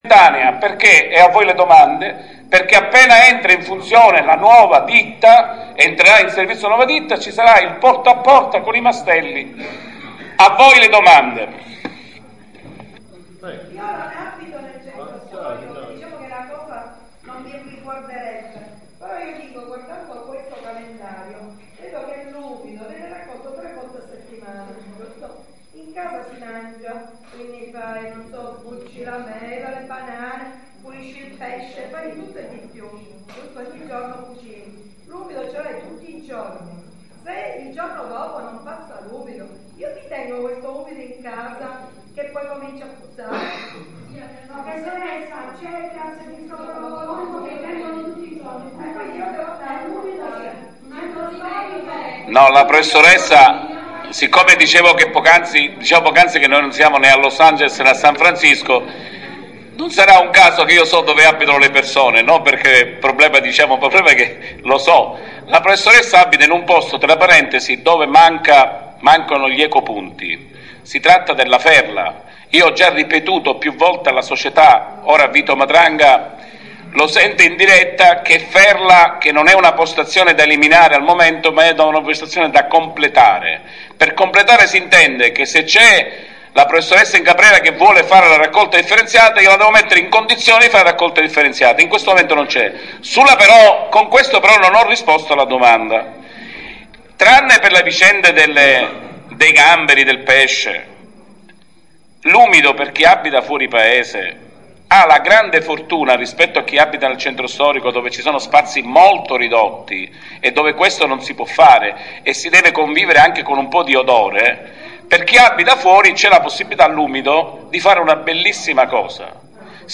54'00 Parte seconda: Dibattito
Parte Prima: Sindaco Lapunzina